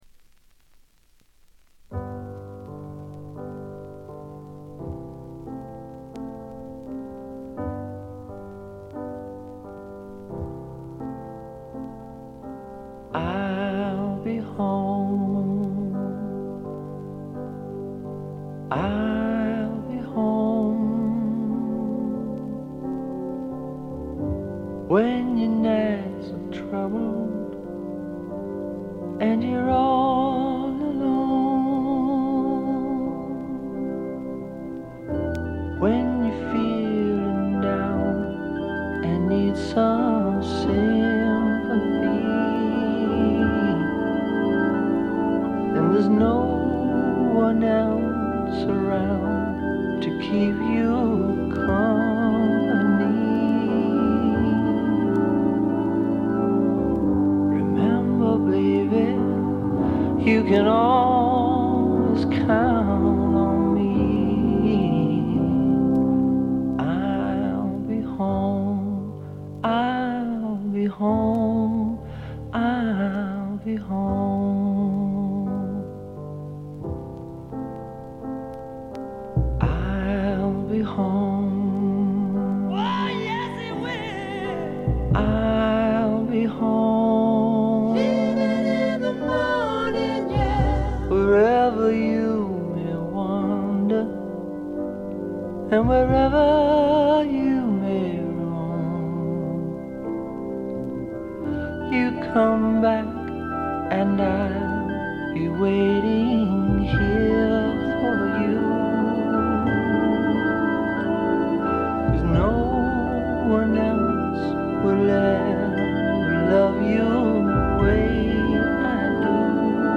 軽微なバックグラウンドノイズ程度。
試聴曲は現品からの取り込み音源です。